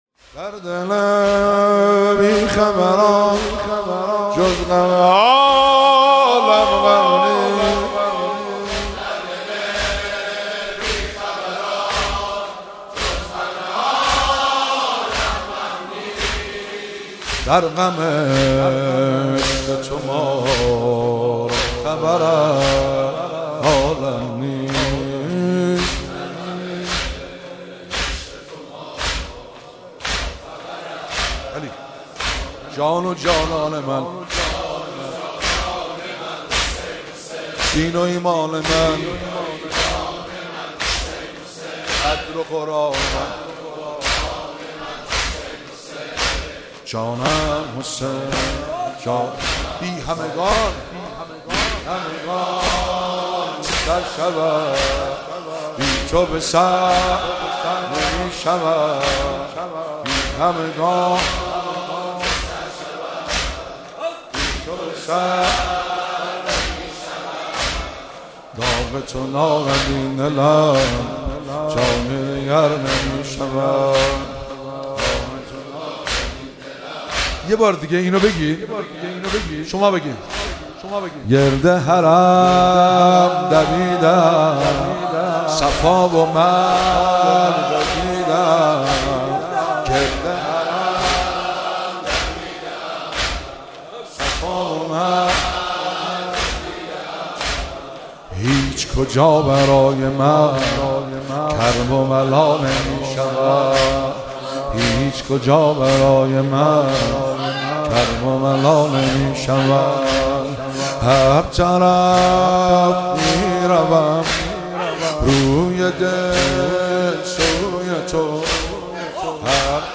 مراسم شهادت امام سجاد(ع)- شهریور 1401